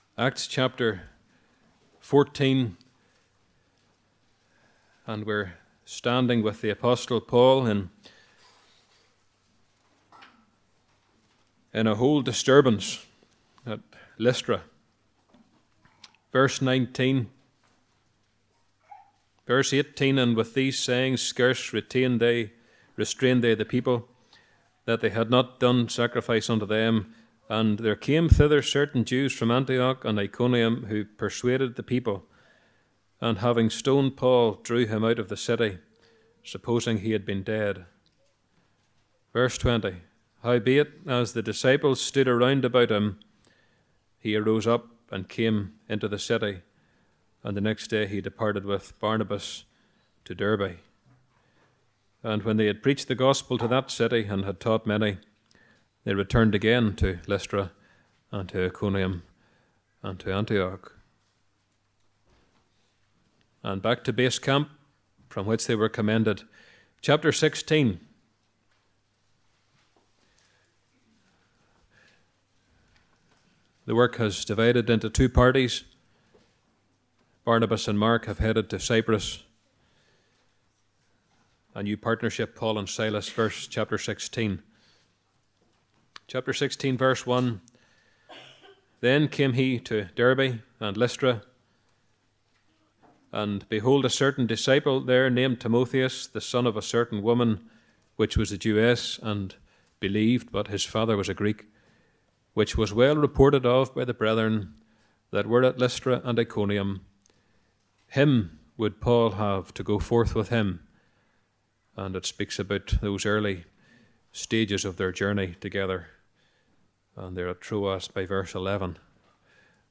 Location: Cooroy Gospel Hall (Cooroy, QLD, Australia)